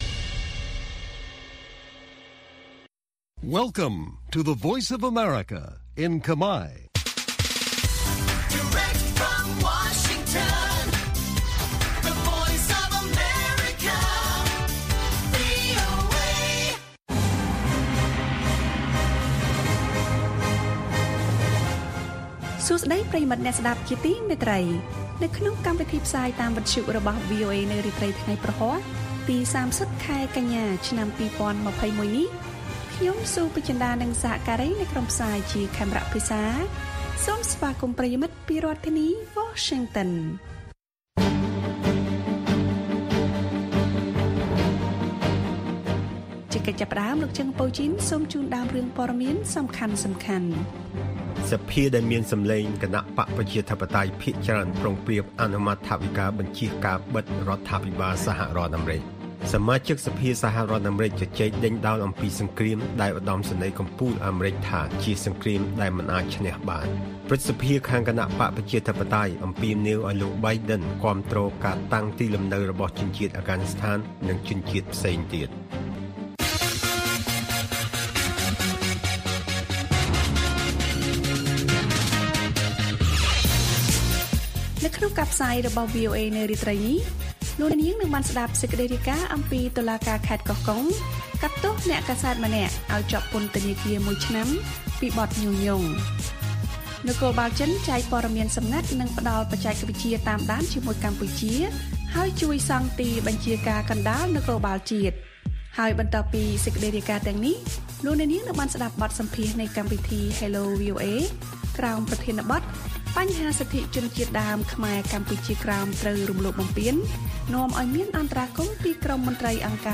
ព័ត៌មានពេលរាត្រី៖ ៣០ កញ្ញា ២០២១